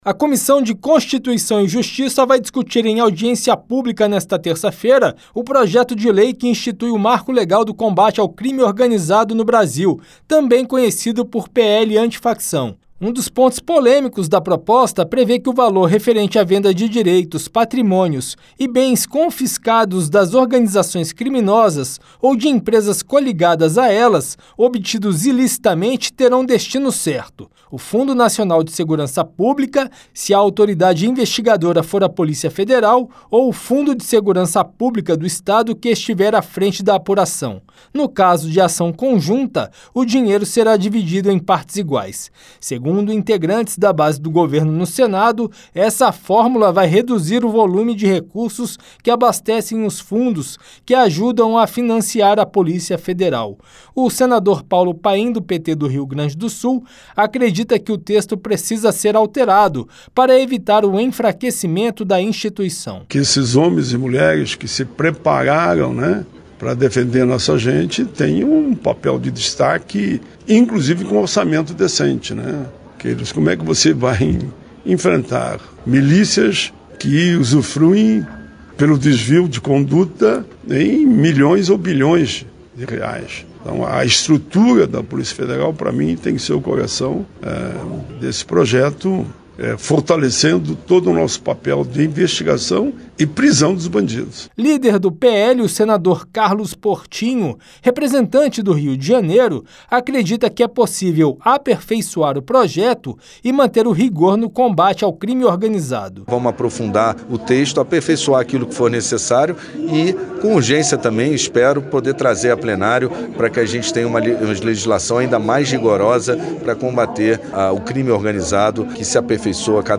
Debate